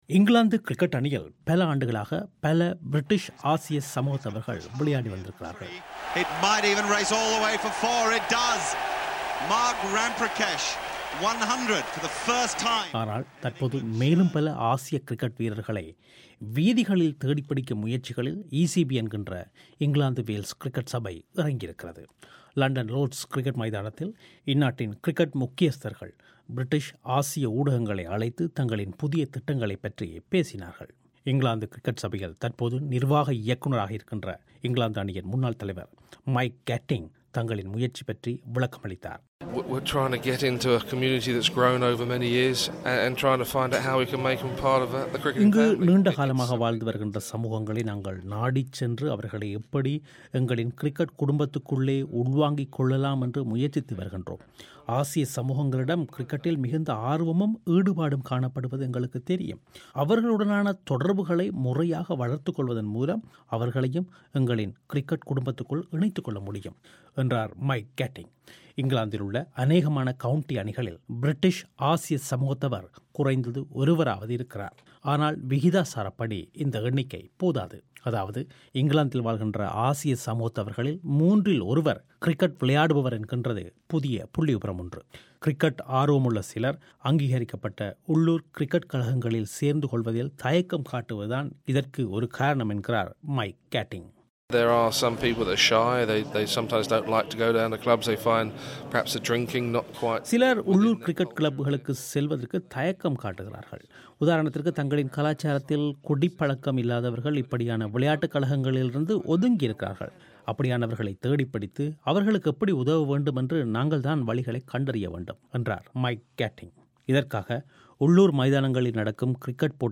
இதுபற்றிய பிபிசியின் செய்திப் பெட்டகத்தை நேயர்கள் இங்கு கேட்கலாம்.